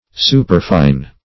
Superfine \Su"per*fine\, a.